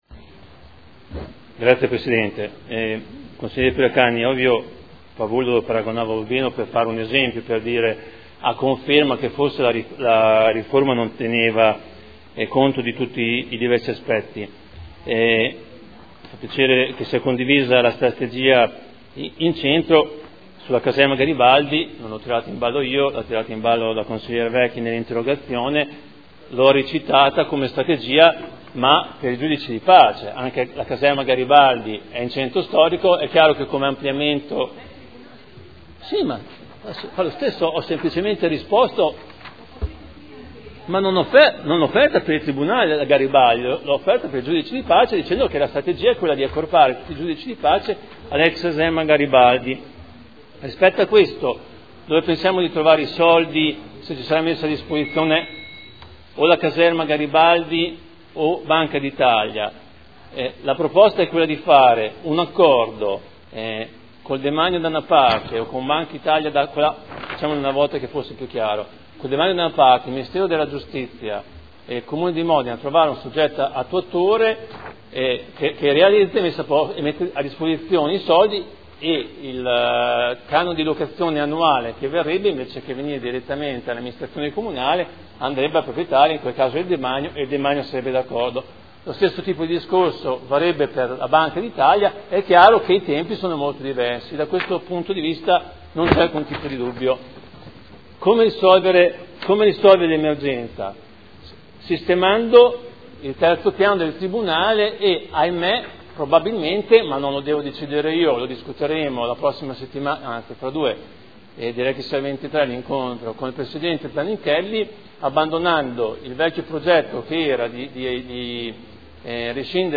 Fabio Poggi — Sito Audio Consiglio Comunale